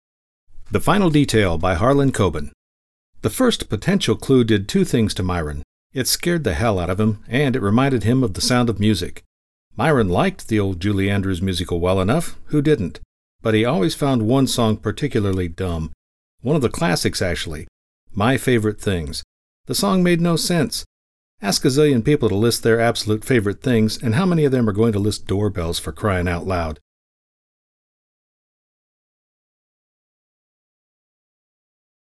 English middle aged guy next door warm believable smooth voice.
middle west
Sprechprobe: eLearning (Muttersprache):